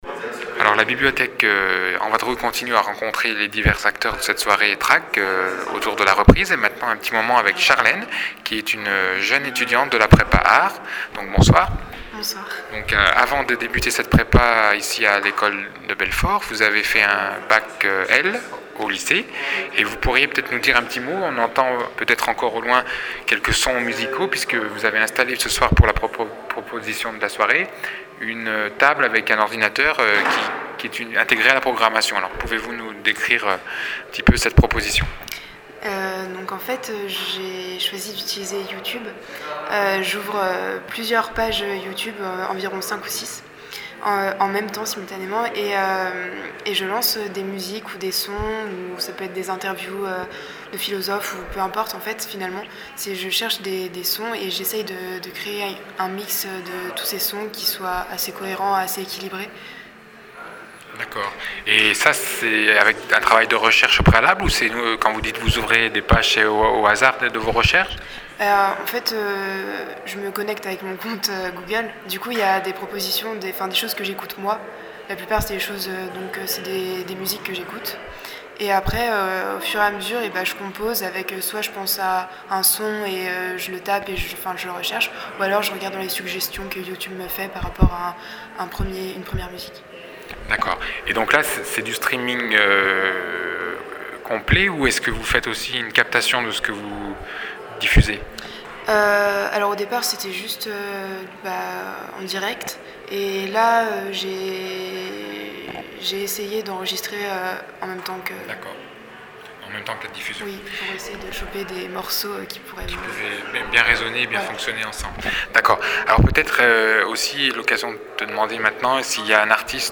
Lieu : École d’art de Belfort